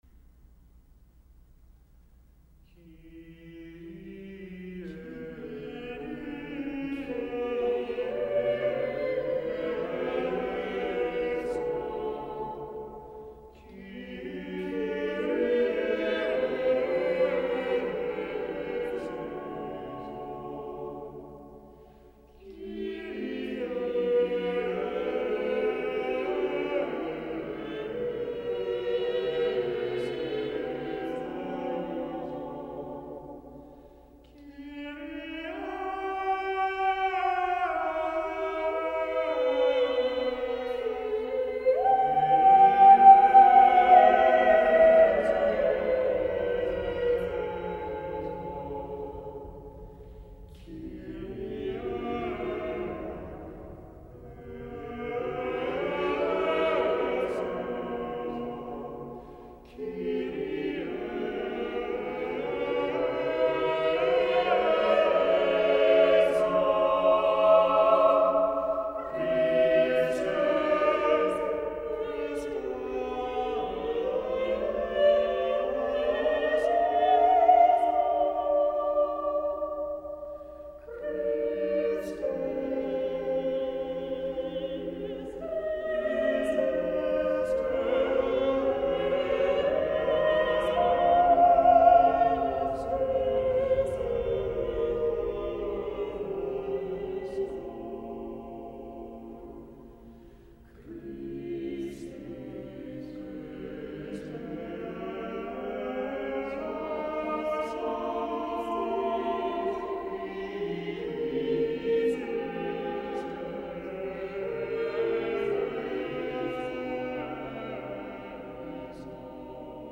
Kyrie Read through - 8 voices
Choral